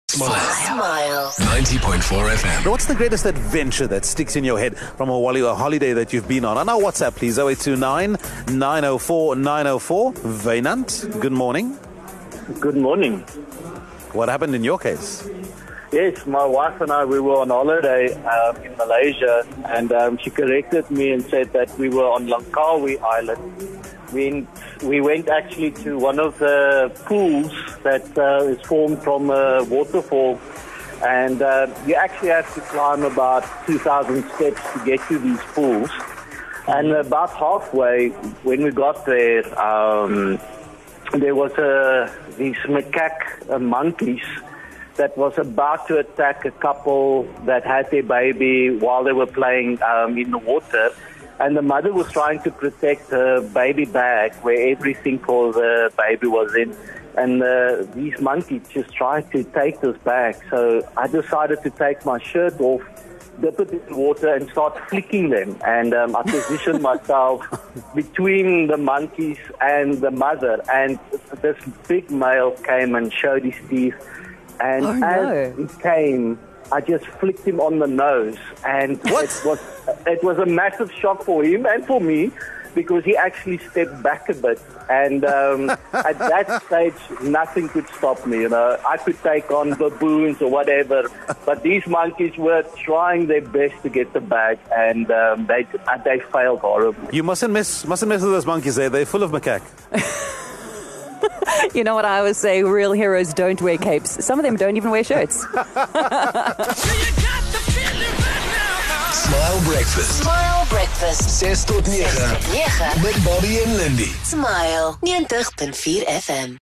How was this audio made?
Recently Smile Breakfast broadcast from Waterstone Village in Somerset west and got talking about adventurous holiday experiences.